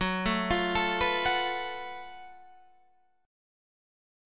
F#mM7bb5 chord